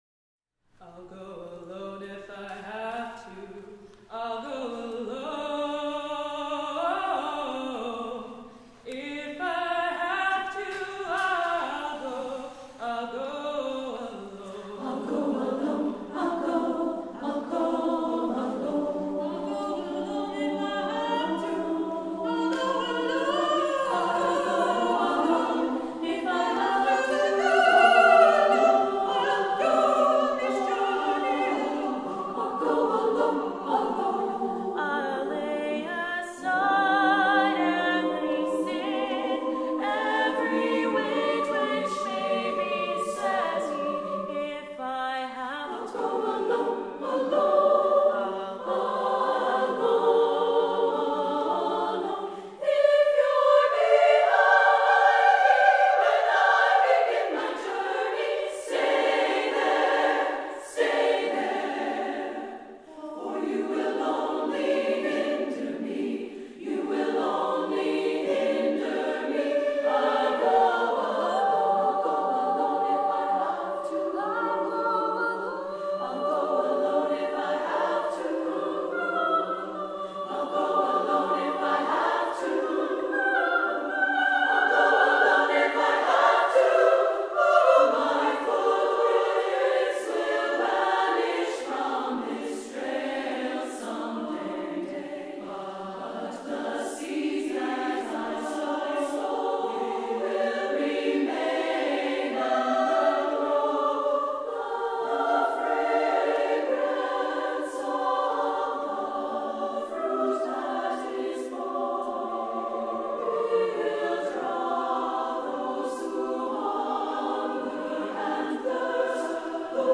SSAA a cappella, soloists
There are short, prominent solos in all voice parts.